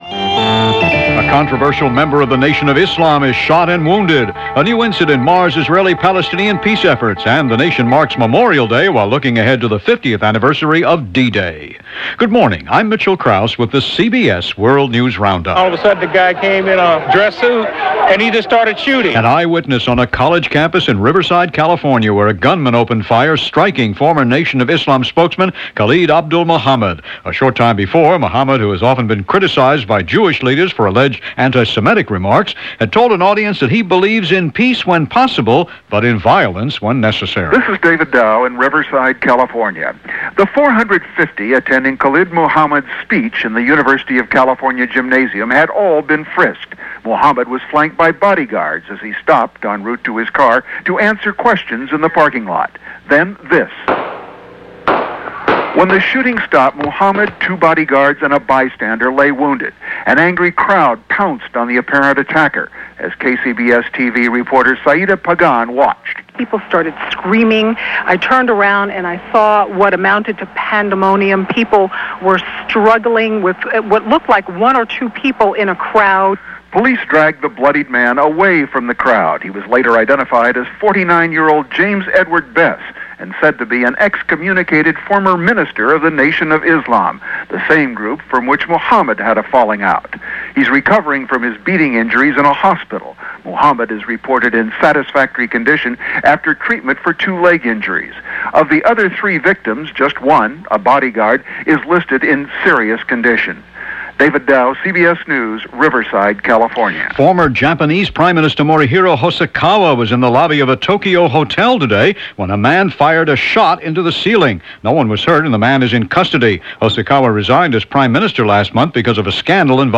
And that’s just a sample of what went on, this May 30, 1994 – as reported by The CBS World News Roundup.